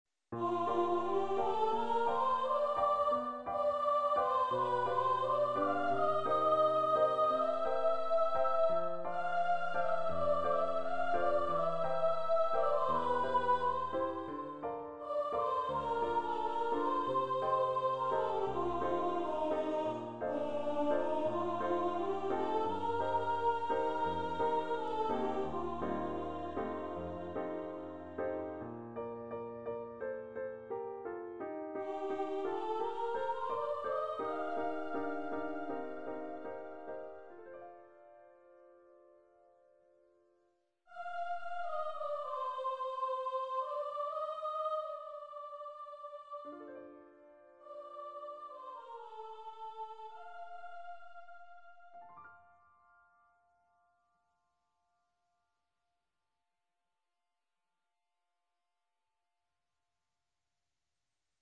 Composer's Demo